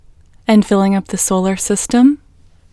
WHOLENESS English Female 12